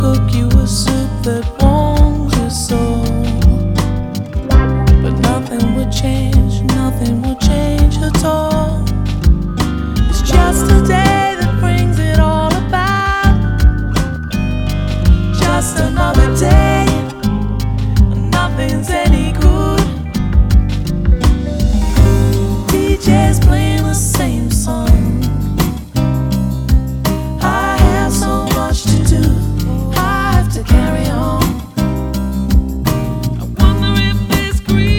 Жанр: Поп музыка / Рок / R&B / Соул